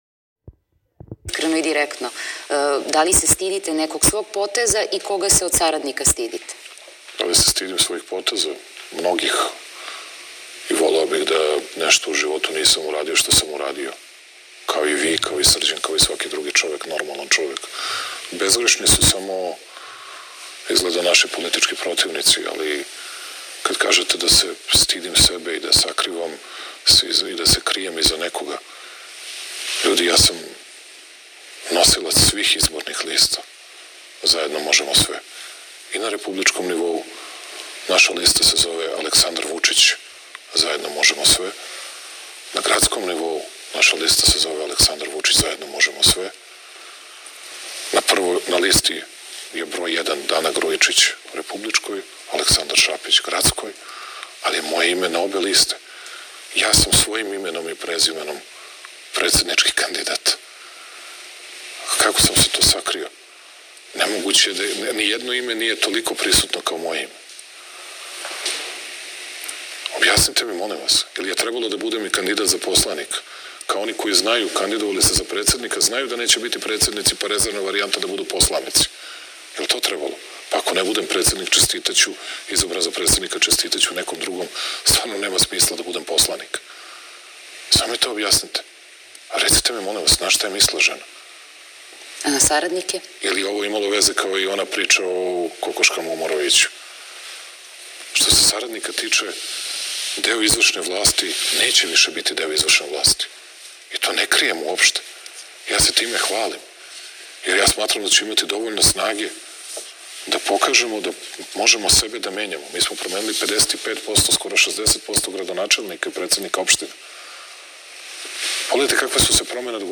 Leskovac više nema finansijskih problema, ima 680 miliona na računu, ali je morao sebe da promeni jer više ne bi bio to što jeste i čovek je veoma odgovorno i ozbiljno postupio“, rekao je sinoć predsednik Srbije Aleksandar Vučić gostujući na televiziji Pink.